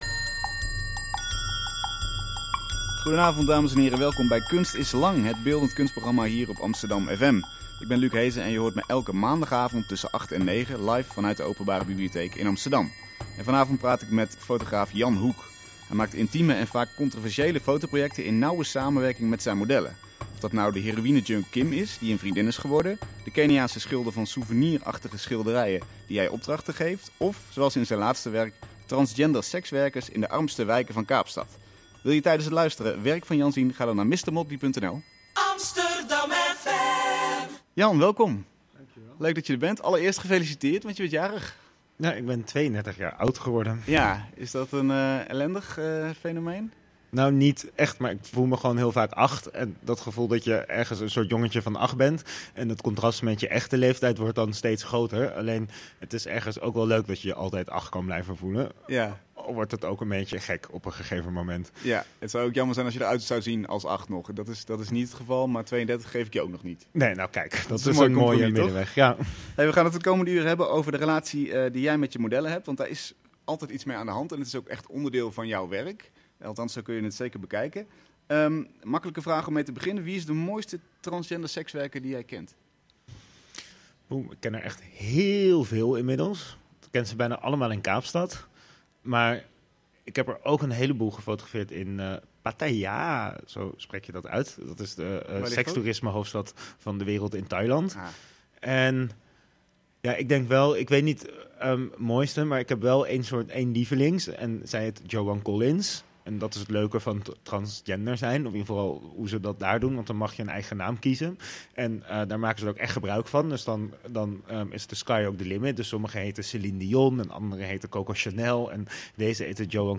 Een mooi gesprek over de (soms verschillende) verwachtingen van fotograaf en gefotografeerde.